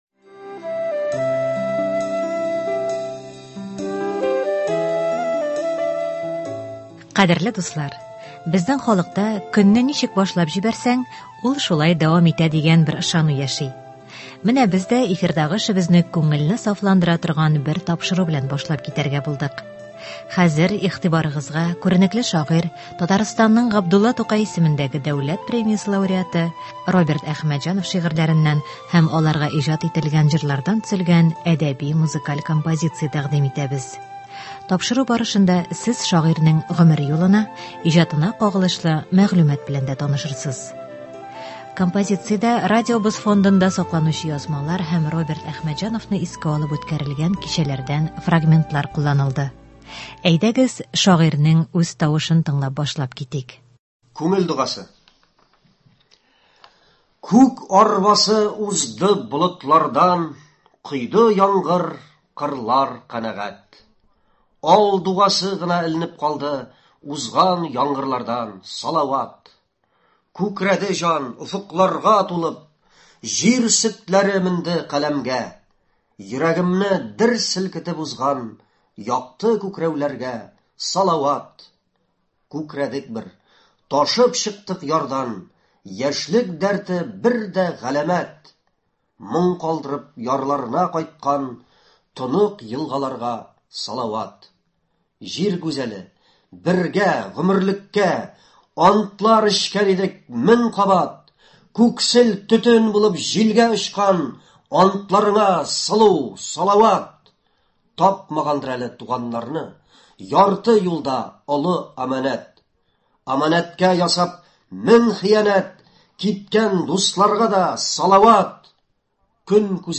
Роберт Әхмәтҗан әсәрләреннән әдәби-музыкаль композиция.